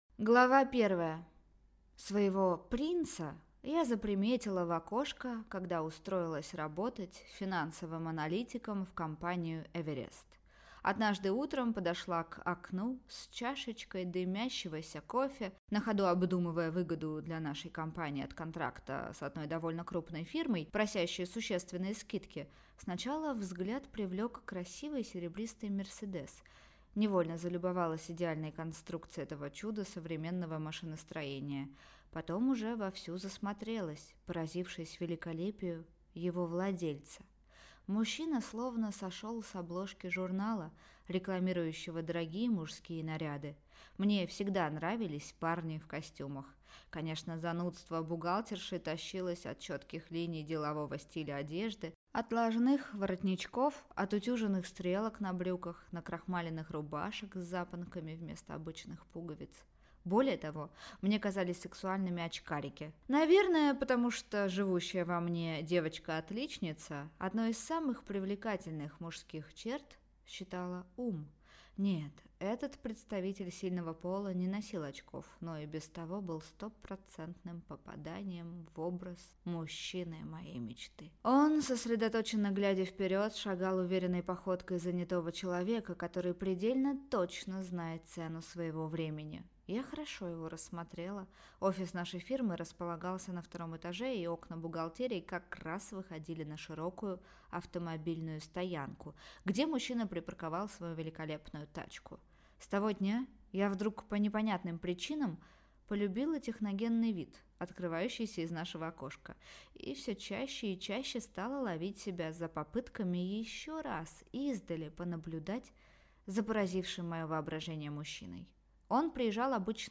Аудиокнига Не смей меня касаться | Библиотека аудиокниг